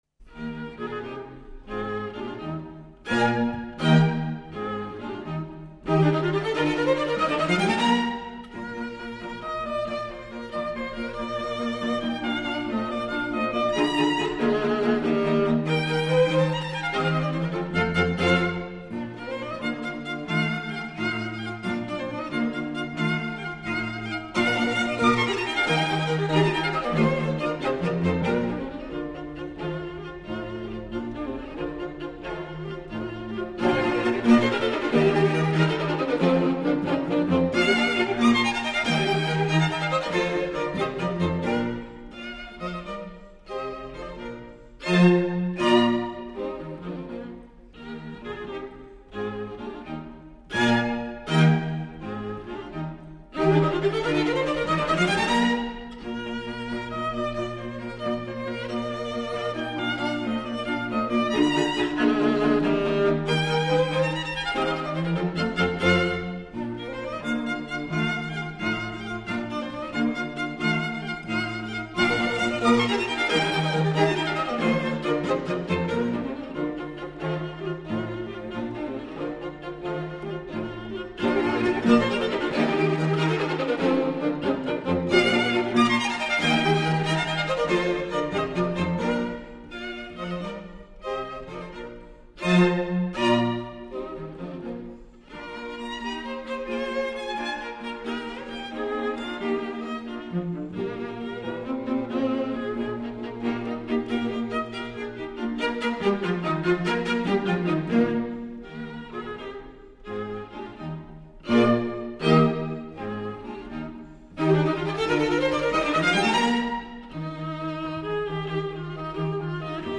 String Quartet in E flat major
Allegro assai